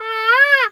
bird_peacock_squawk_04.wav